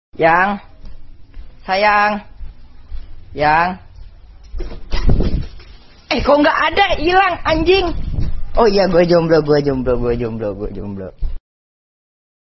Genre: Nada notifikasi
nada-notifikasi-wa-lucu-story-wa-orang-jomblo.mp3